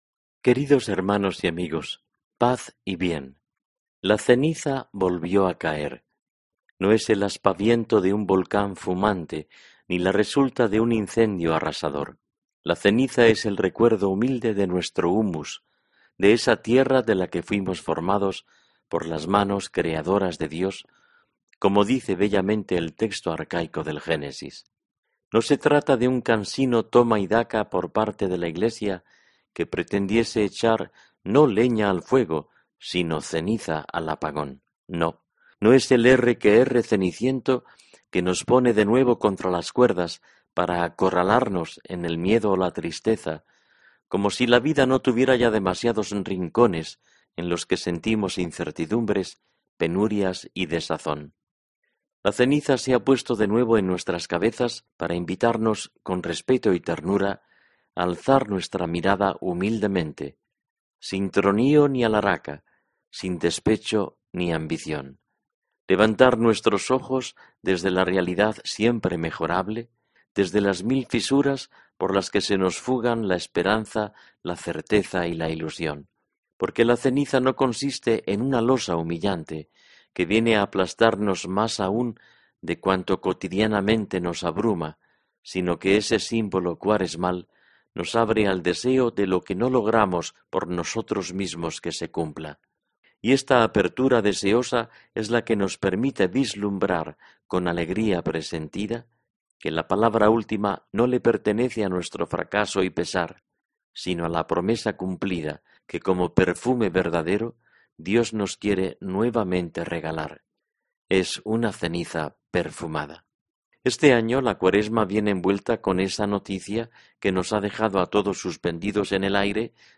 Programa de noticias de la iglesia de Asturias